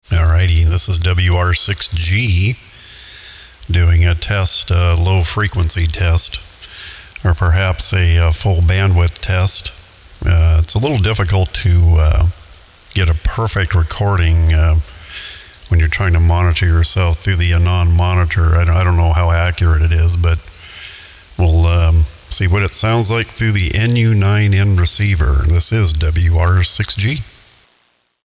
MP3 Off-Air Hi-fi SSB, eSSB Station Recordings